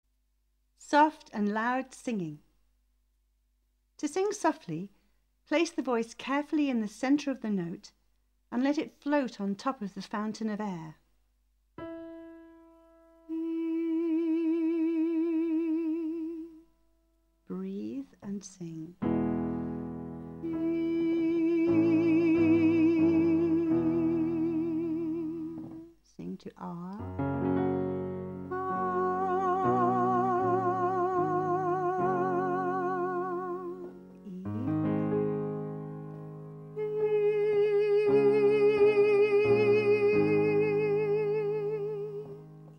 The 'Know Your Voice' CD is a clear and easy to follow explanation of singing technique with demonstrations and exercises for each aspect of producing the voice.